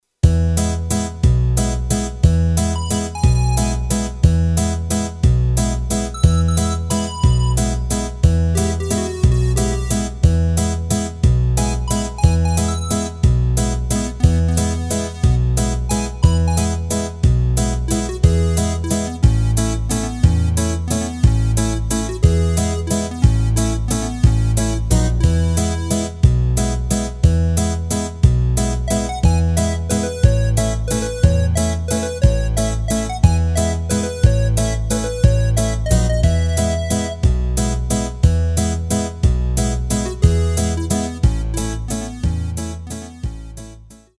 Rubrika: Folk, Country
Karaoke